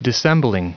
Prononciation du mot dissembling en anglais (fichier audio)
Prononciation du mot : dissembling